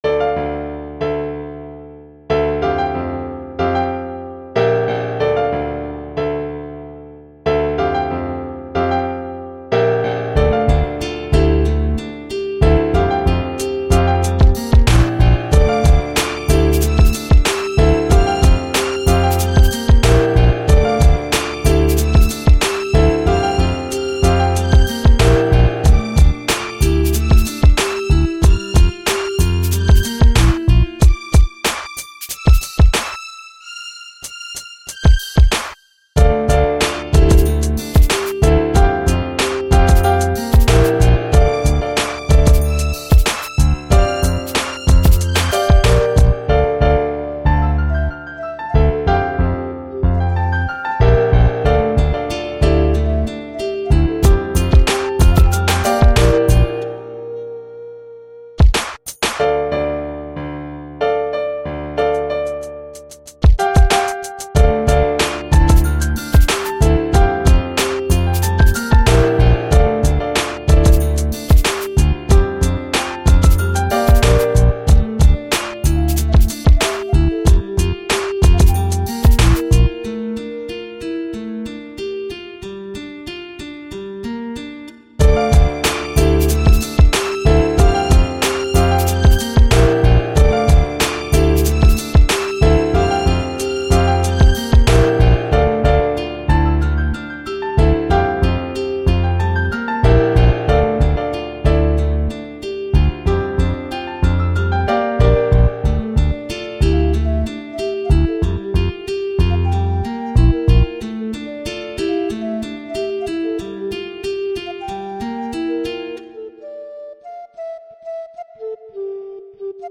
• Исполняет: Борзые Люди
• Жанр: Хип-хоп